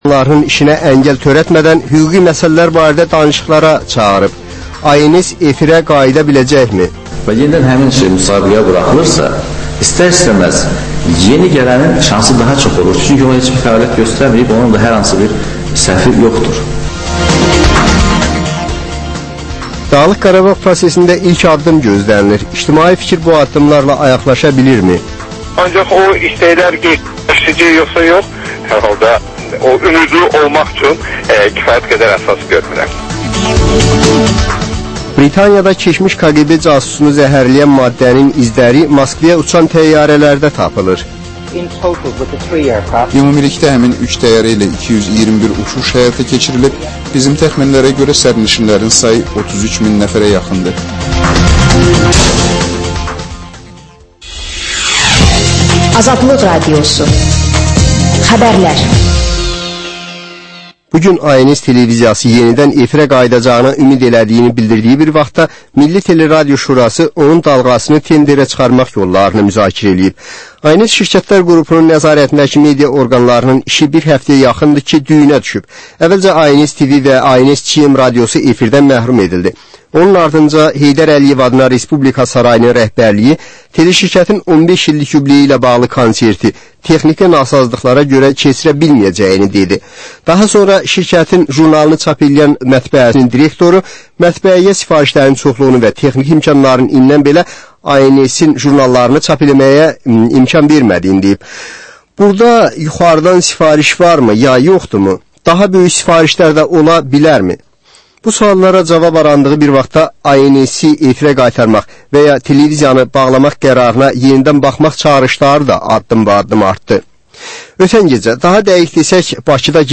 Reportaj, təhlil, müsahibə